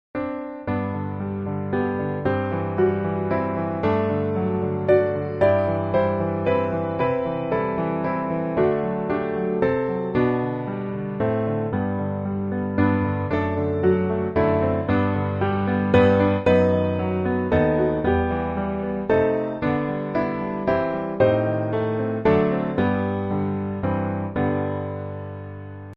G Majeur